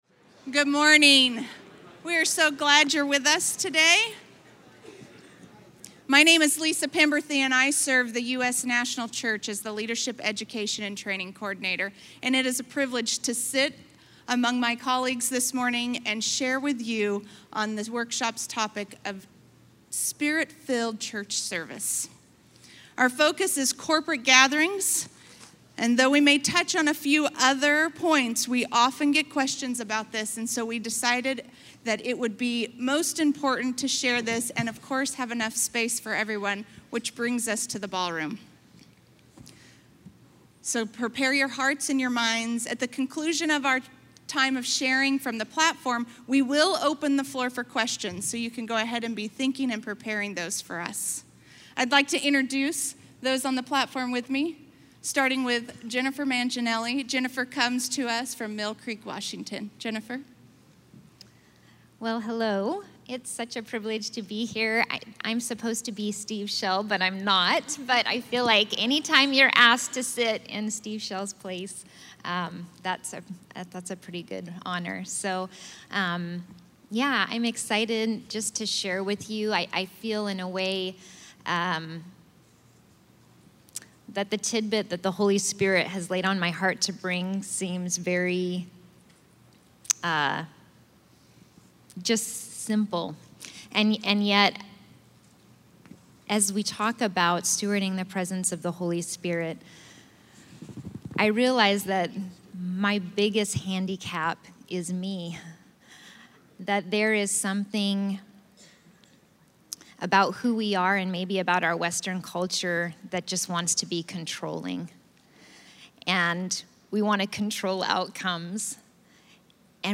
A panel of Foursquare leaders discusses allowing the Holy Spirit to lead the local church, in this workshop recorded live at Foursquare Connection 2018.